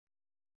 ♪ moṭṭekāṛa